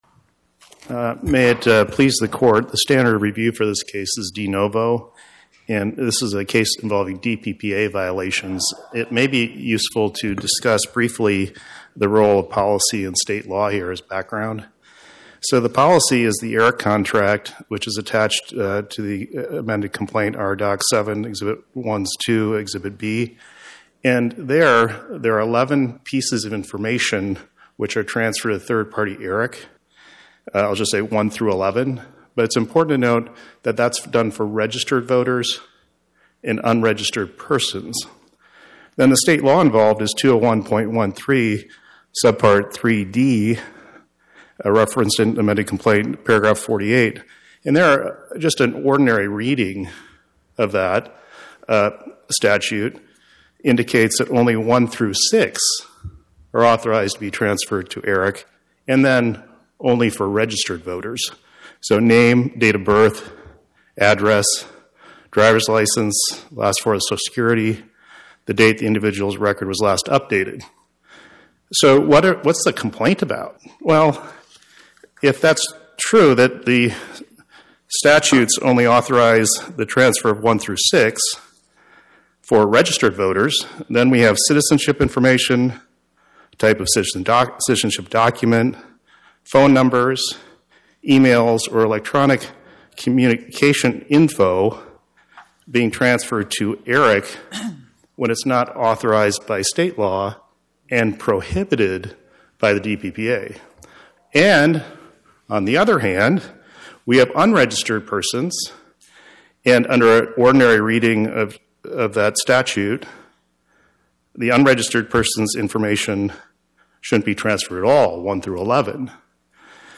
federal court hearing